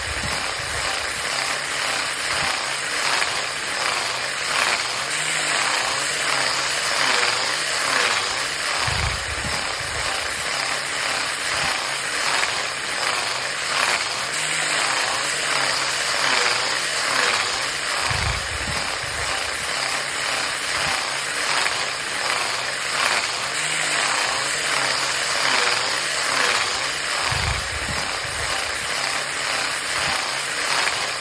Tiếng Chà giấy Giáp, giấy Nhám… bằng máy mài cầm tay, máy mơn…
Thể loại: Tiếng động
Description: Tiếng chà giấy giáp, giấy nhám, còn gọi giấy ráp, giấy chà, nhám nước, nhám vòng, vang lên rè rè, vù vù, xoẹt xoẹt khi máy mài cầm tay lao đi: máy mài góc, máy chà nhám, máy mơn (máy đánh bóng), máy rung quỹ đạo.
tieng-cha-giay-giap-giay-nham-bang-may-mai-cam-tay-may-mon-www_tiengdong_com.mp3